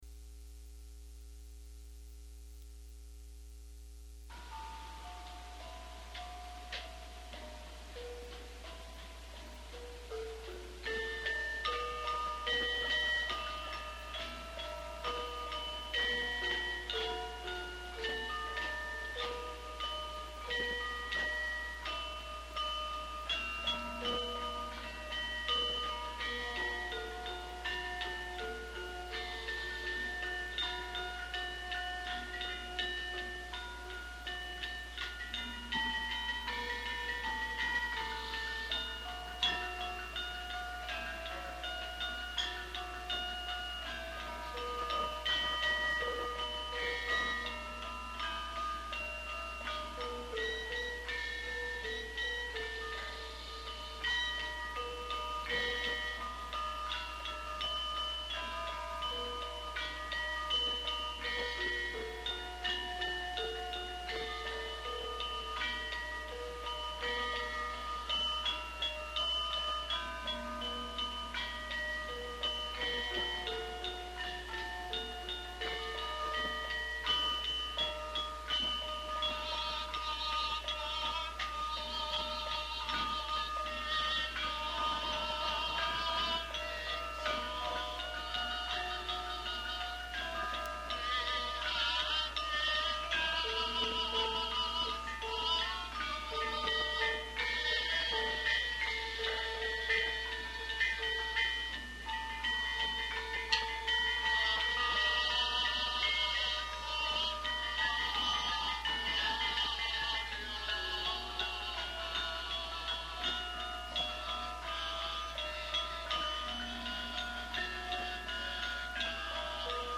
Group:  Gamelan Nyai Saraswati
Chapel Hill Museum
03_Ladrang_Sri_Sunuba_pelog_nem.mp3